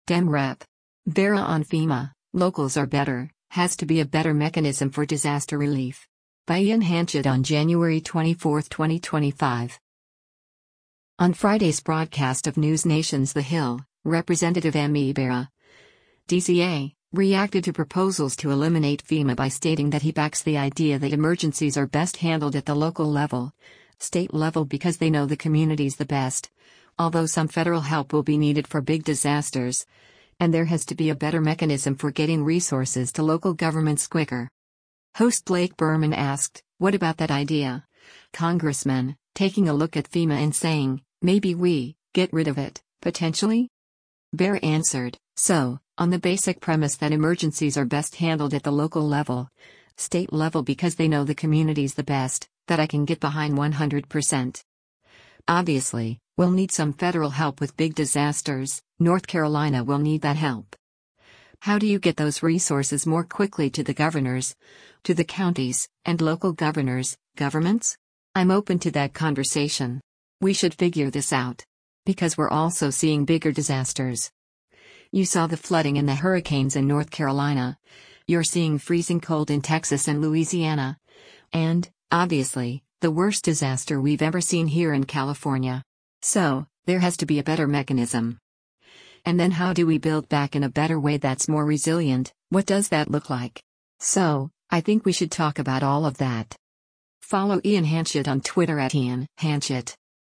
On Friday’s broadcast of NewsNation’s “The Hill,” Rep. Ami Bera (D-CA) reacted to proposals to eliminate FEMA by stating that he backs the idea “that emergencies are best handled at the local level, state level because they know the communities the best,” although some federal help will be needed for big disasters, and “there has to be a better mechanism” for getting resources to local governments quicker.